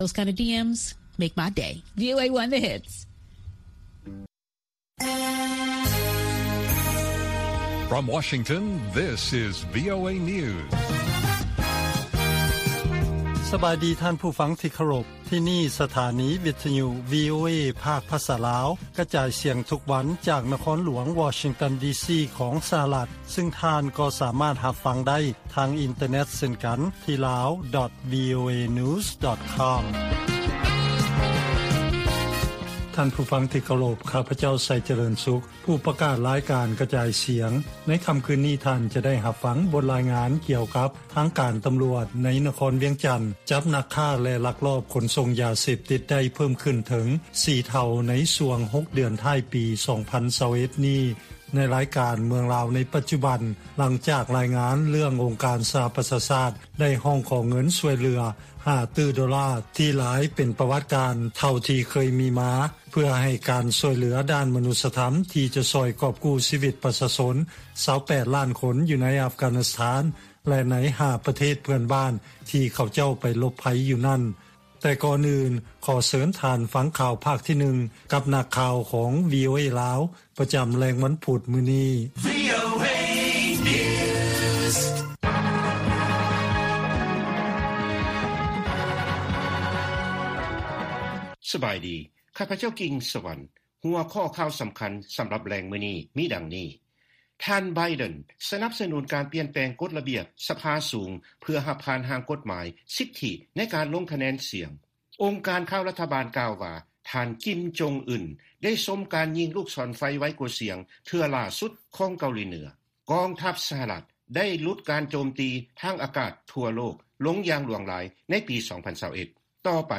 Embed ລາຍການກະຈາຍສຽງຂອງວີໂອເອ ລາວ: ທ່ານ ໄບເດັນ ສະໜັບສະໜູນ ການປ່ຽນແປງ ກົດລະບຽບ ສະພາສູງ ເພື່ອຮັບຜ່ານ ຮ່າງກົດໝາຍ ສິດທິໃນການລົງຄະແນນສຽງ Embed The code has been copied to your clipboard.